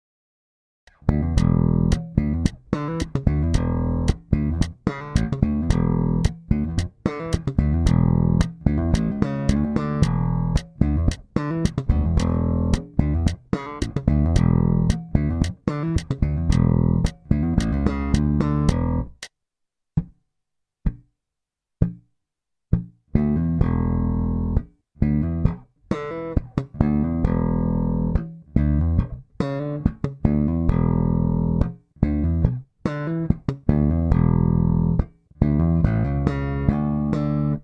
L16 Basic slap bass in E
A basic slap & pop line in Em or E7 (no third so it can be Major or minor both). Typical b7 and 6 to octave.
L16BasicslapinE.mp3